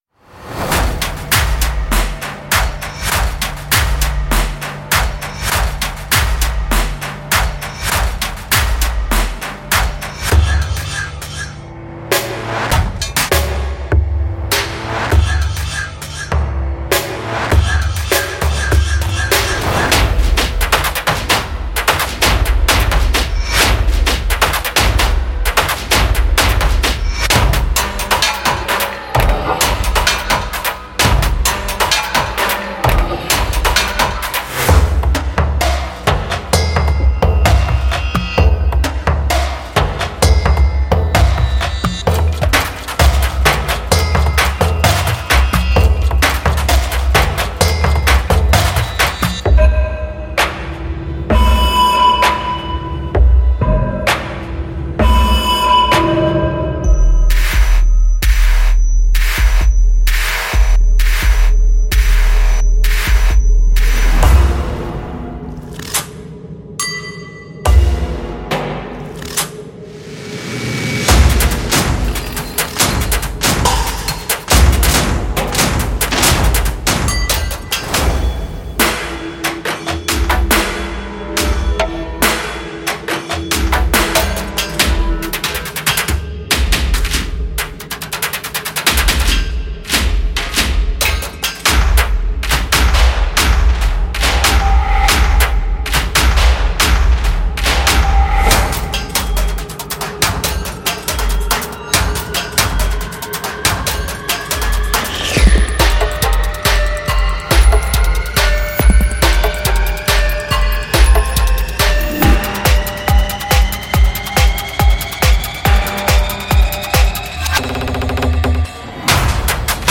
......::::::预览PRODUCT DEMO/PREViEW ::::::......
所有声音都是超详细的，分层的和可操作的，可以轻松地添加到您的项目中。